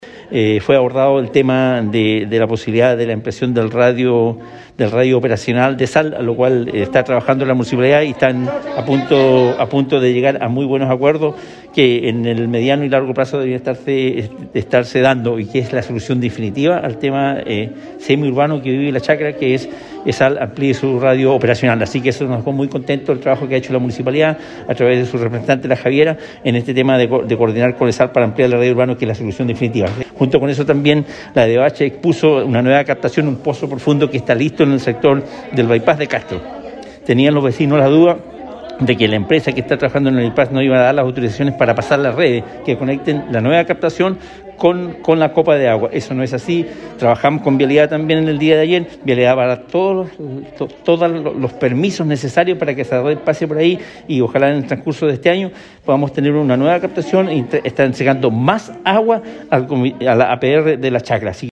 Desde la gobernación provincial, el titular en el cargo, Pedro Andrade, recalcó la importancia de los acuerdos adoptados entre los vecinos y las entidades presentes, es decir, la Autoridad Sanitaria, Obras Hidráulicas y el municipio de Castro, tras las manifestaciones por la prolongada falta de agua.